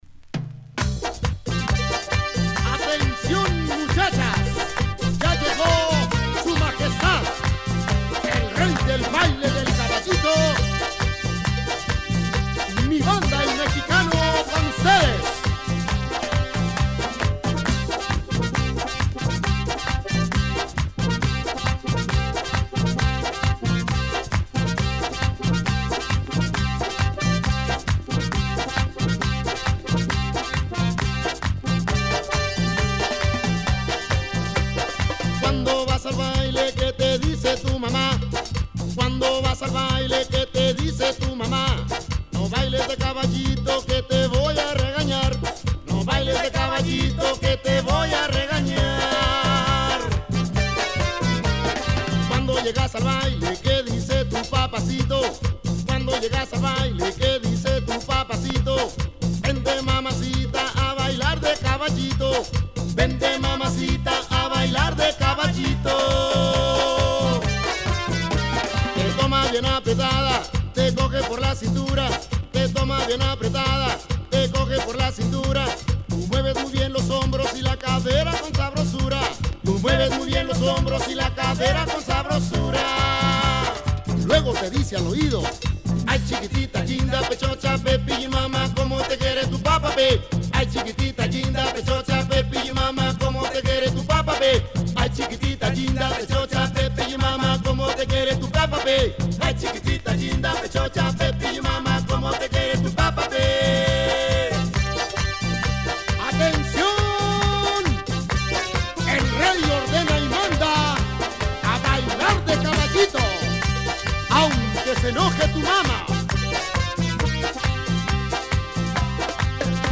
techno banda style